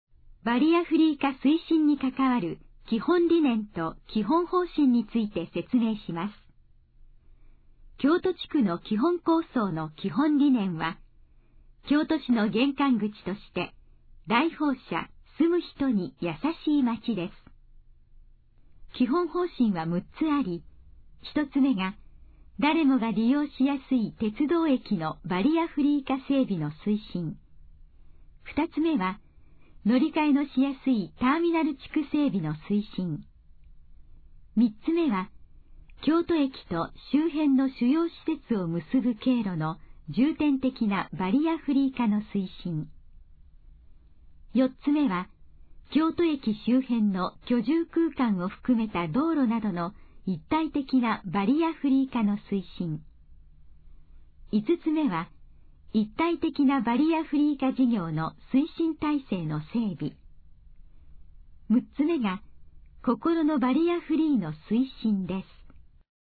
このページの要約を音声で読み上げます。
ナレーション再生 約130KB